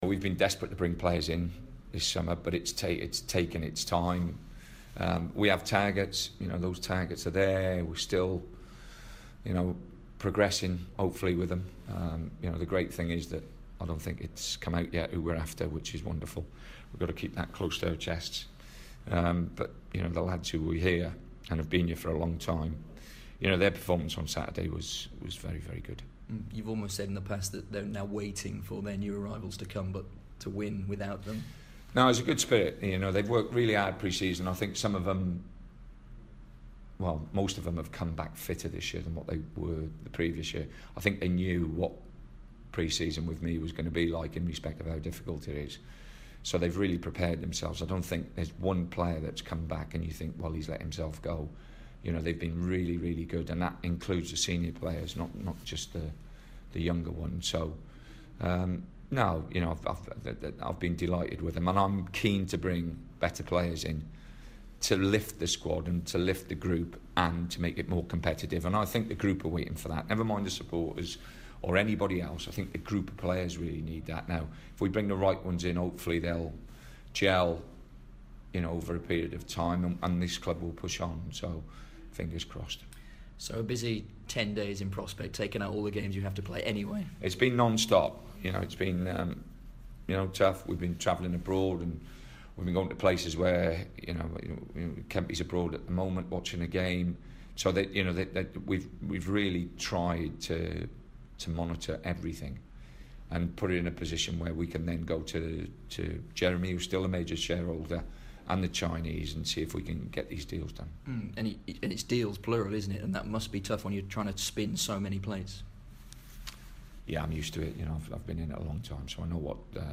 Baggies boss Tony Pulis speaks to BBC WM about the prospect of new arrivals...